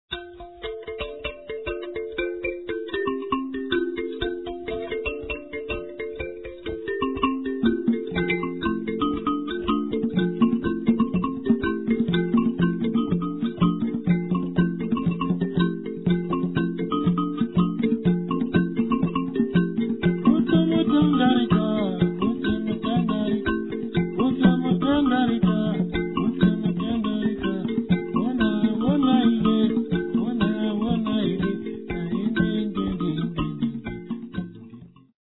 the mbira.